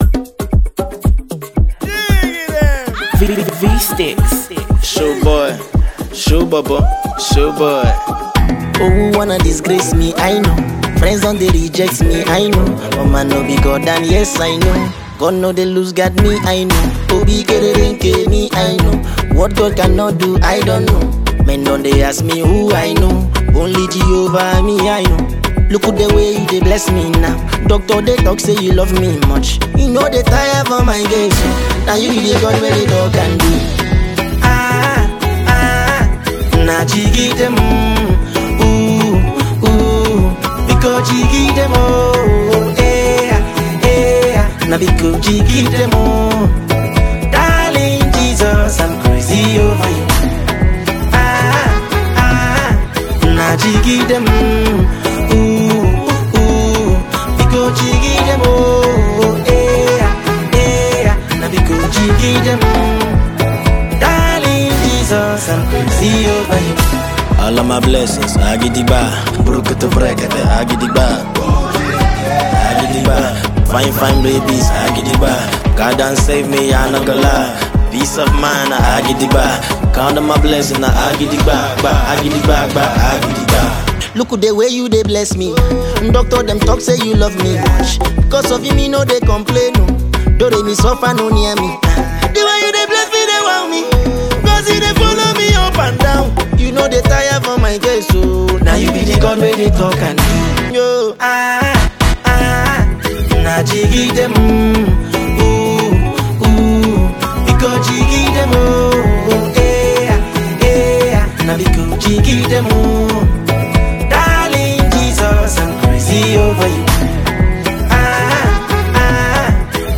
With its upbeat tempo and catchy chorus